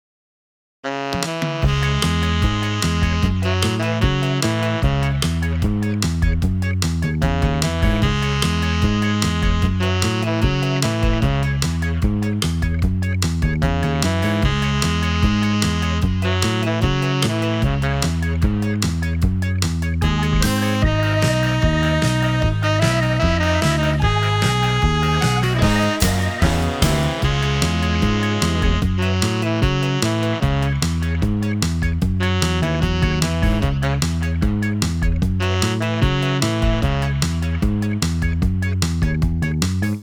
remake